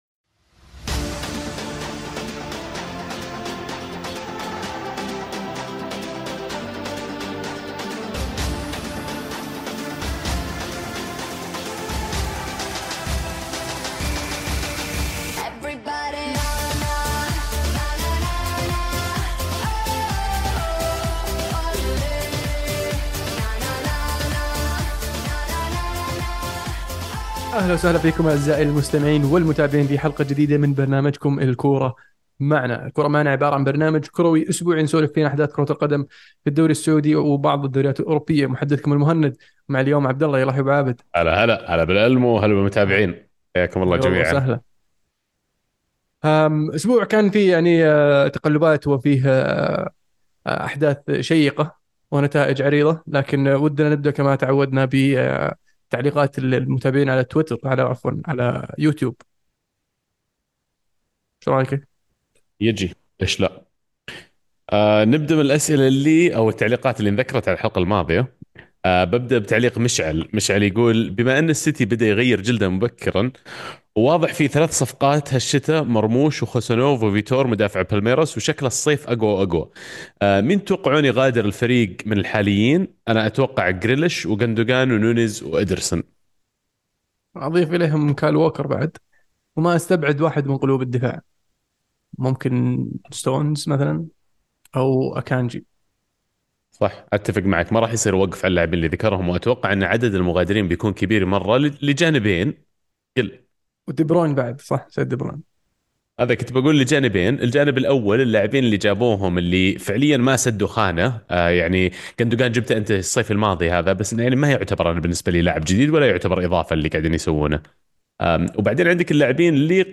بودكاست "الكورة معنا" برنامج صوتي كروي اسبوعي من تقديم شباب عاشقين لكرة القدم، يناقشون فيه اهم الاحداث الكروية العالمية والمحلية خلال الأسبوع بعيد عن الرسمية.